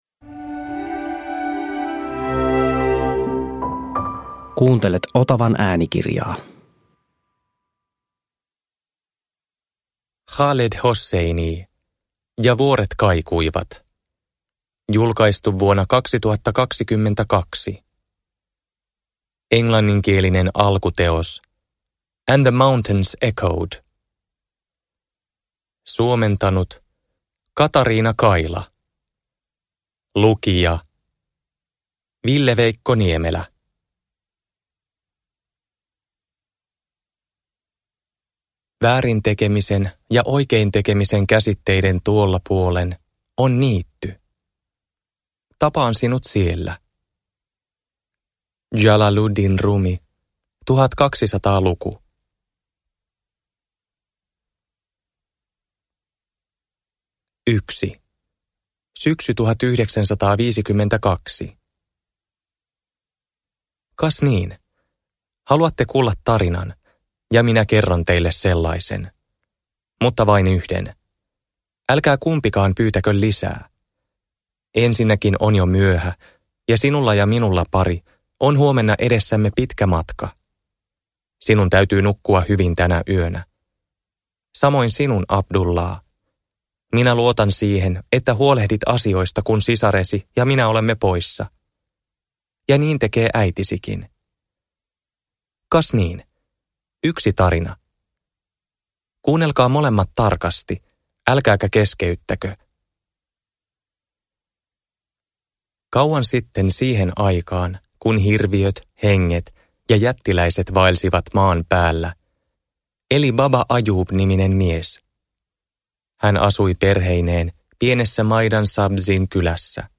Ja vuoret kaikuivat – Ljudbok – Laddas ner